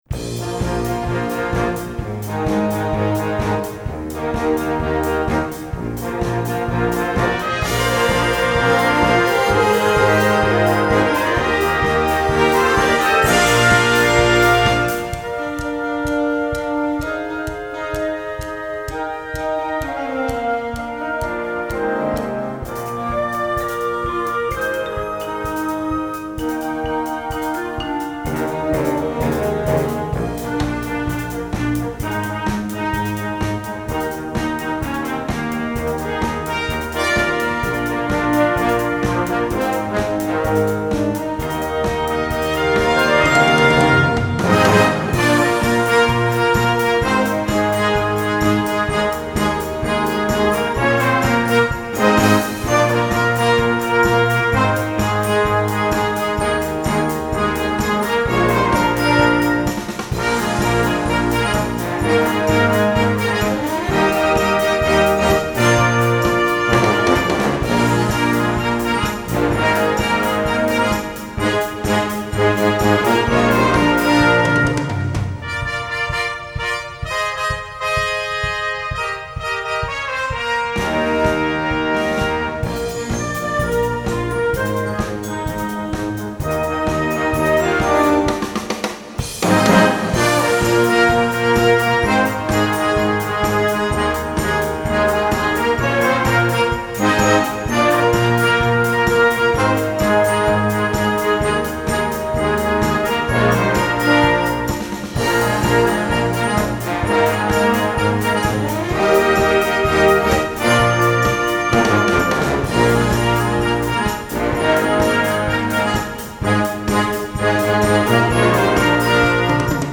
Gattung: Moderner Einzeltitel für Jugendblasorchester
Besetzung: Blasorchester